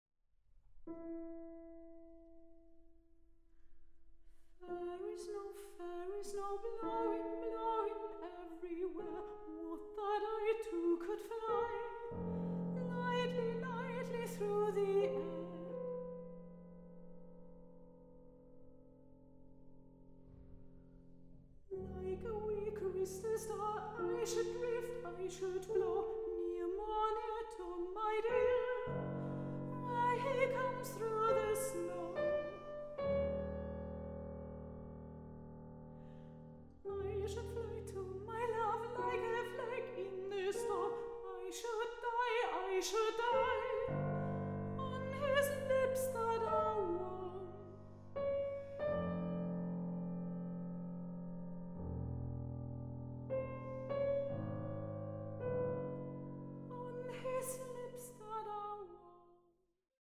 Klarinette
Klavier
Sopran
Aufnahme: Tonstudio Ölbergkirche, Berlin, 2023